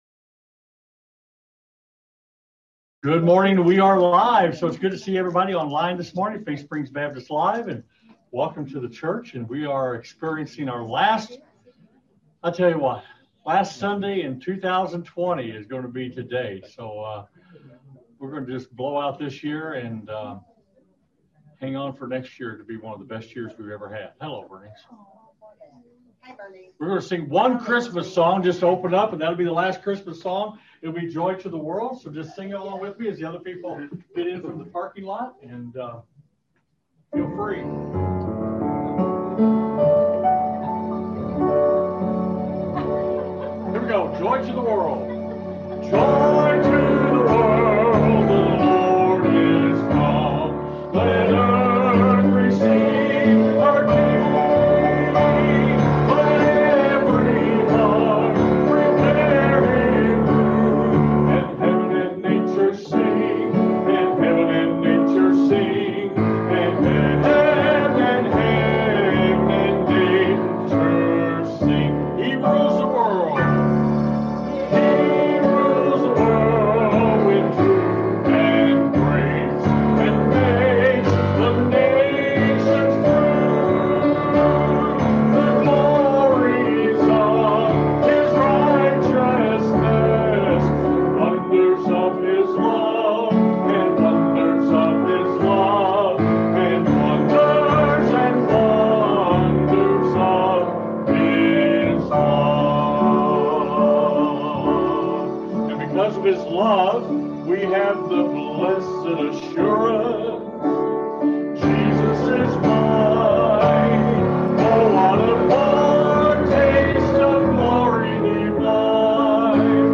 FaithSprings Baptist LIVE, December 27, 2020